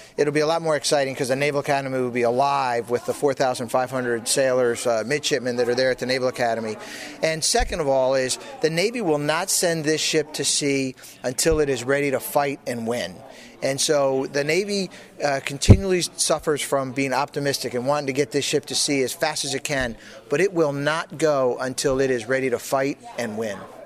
Rear Admiral Frank Thorp, who chairs the commissioning committee, says the date of the event remains fluid, and should take place this fall in Annapolis, Maryland: